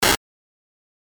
電撃 B03 short
/ F｜演出・アニメ・心理 / F-30 ｜Magic 魔法・特殊効果 / 電気
ビリッ